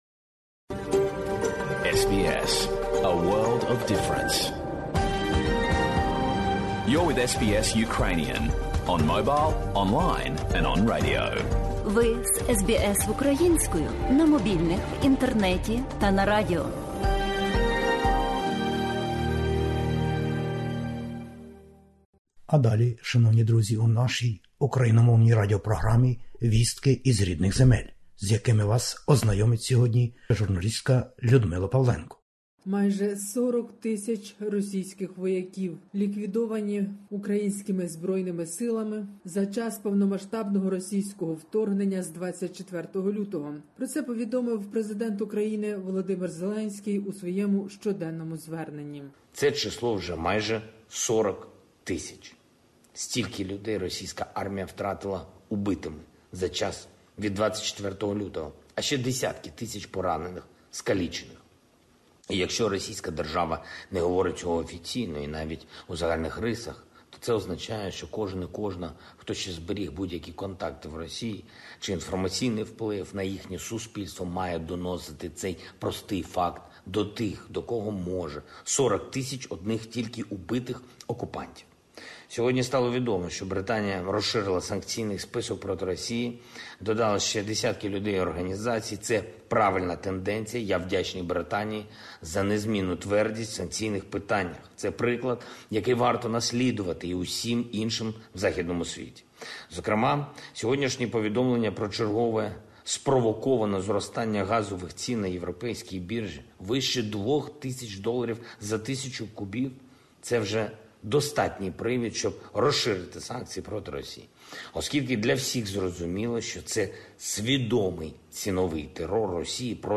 Добірка SBS новин українською мовою. Війна в Україні - Звернення Президента України.